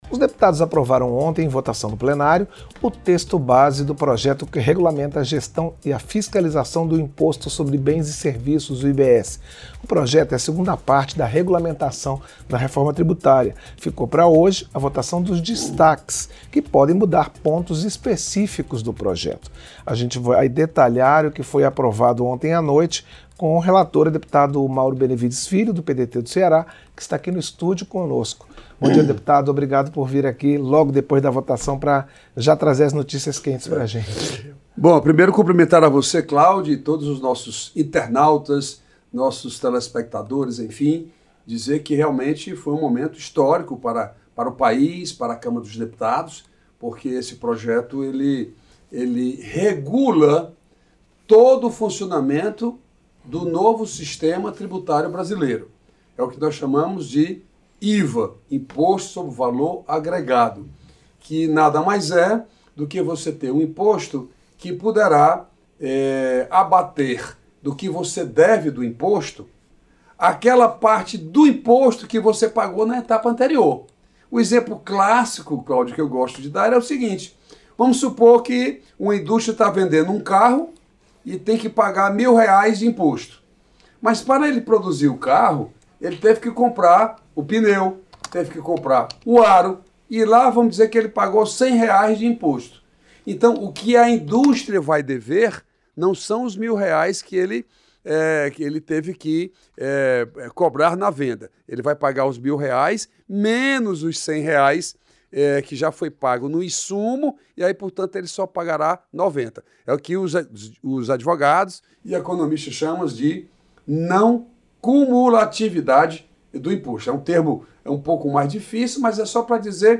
Entrevista - Dep. Mauro Benevides Filho (PDT-CE)